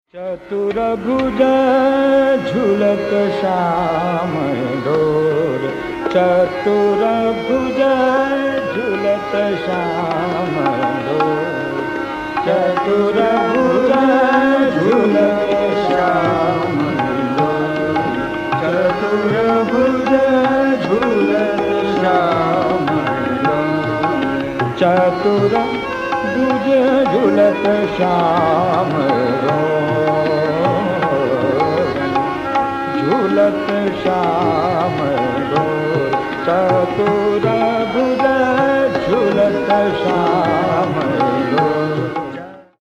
Aroha: SRmR, mRP, mPDNS
Avroh: SDnP, mPDnP, nPmgR, RgRS
—Pandit Jasraj (2005)—
[refrain, e.g. 0:01] mPDNS(NS) (P)nPP, mg(m) RS(nS)R S…
• Tanpura: Sa–Pa